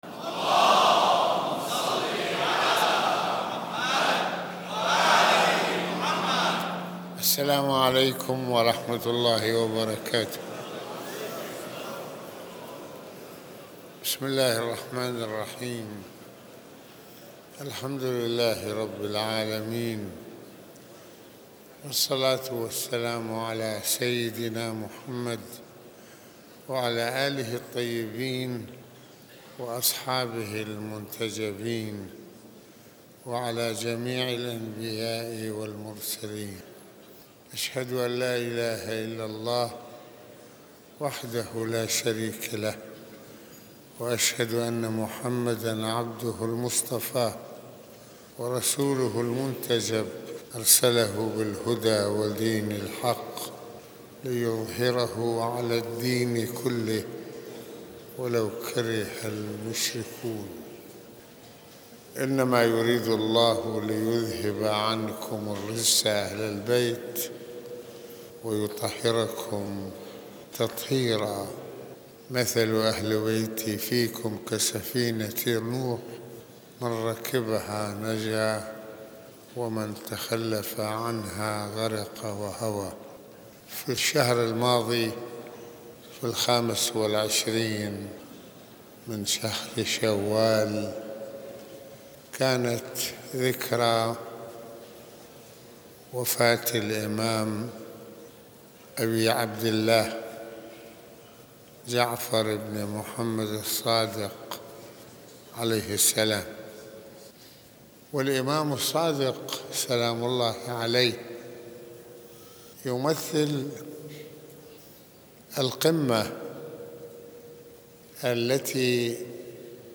- المناسبة : خطبة الجمعة المكان : مسجد الإمامين الحسنين (ع) المدة : 34د | 14ث المواضيع : الإمام جعفر الصادق (ع) رائد العلم ومجدد الإسلام - الإمام الصادق صانع العلماء والمفكرين - علاقته بالخلفاء - الحرية الإنسانية في فكر الإمام الصادق (ع) - الحوار مع الملحدين.